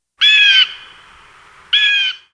eagle2.wav